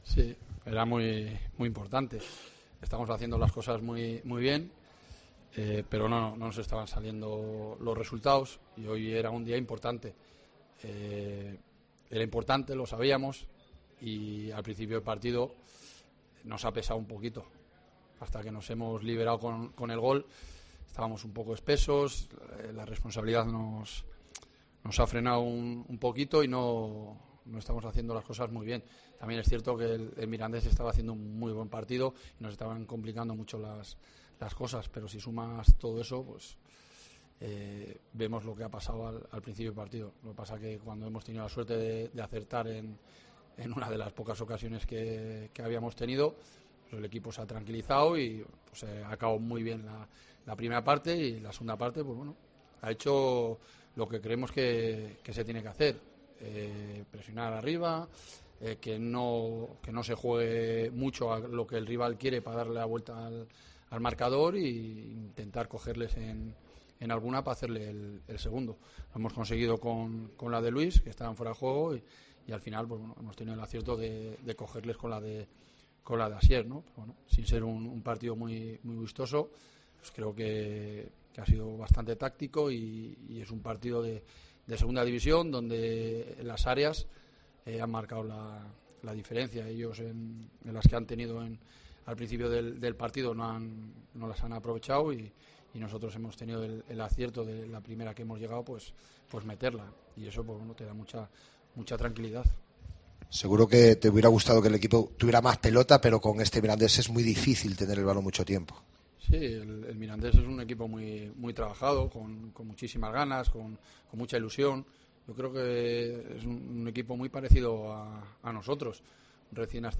Escucha aquí las palabras del míster de la Ponferradina tras la victoria 2-0 ante el Mirandés